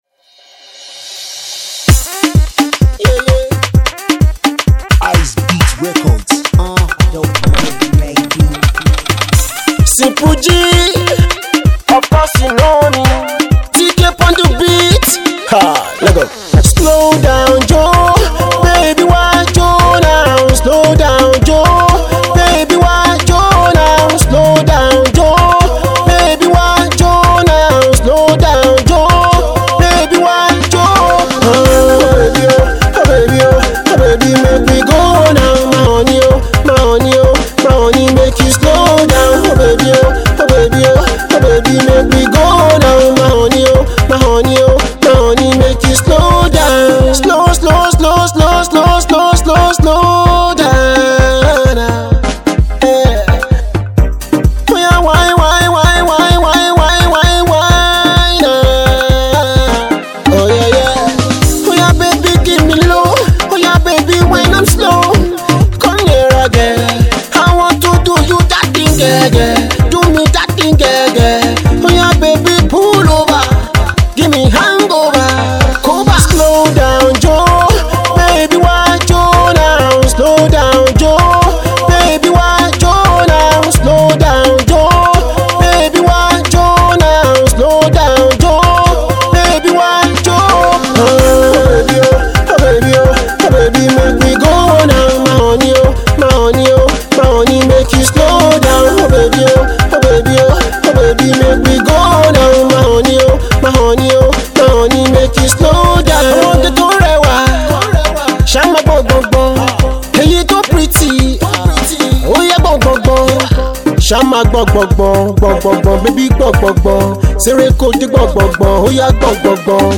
The mid-tempo beat